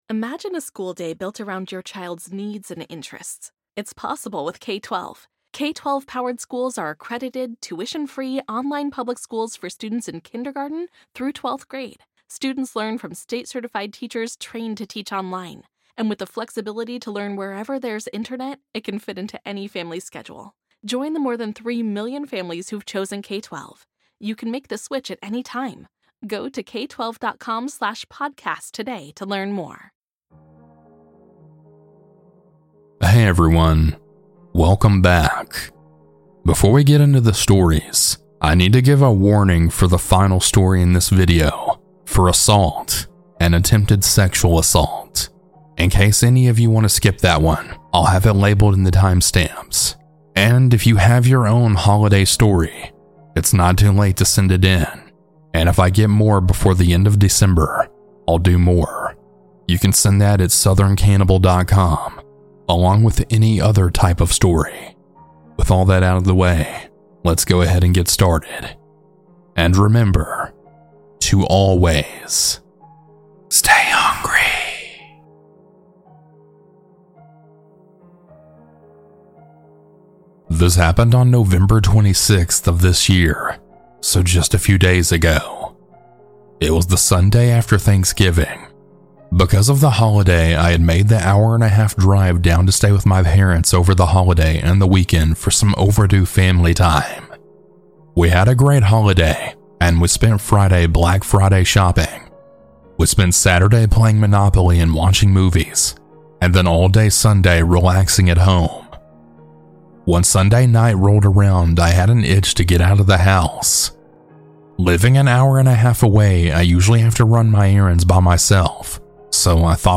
Huge Thanks to these talented folks for their creepy music!